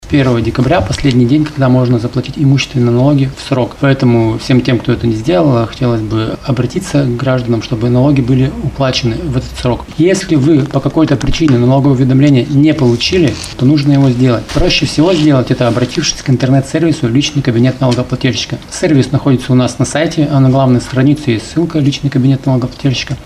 на пресс-конференции Интерфакс-Урал.